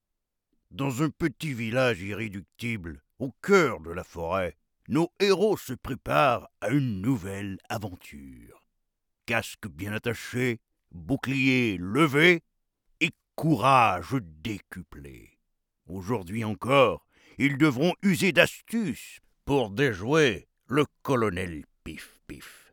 Diep, Vertrouwd, Volwassen
Explainer